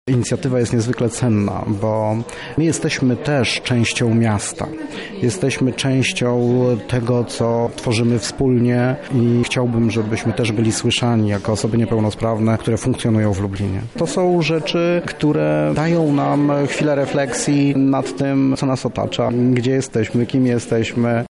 Jak co roku w dzień urodzin Józefa Czechowicza lublinianie przeczytali „Poemat o Mieście Lublinie”.
Wydarzenie odbyło się wczoraj na Zamku Lubelskim.
czytanie-Czechowicza.mp3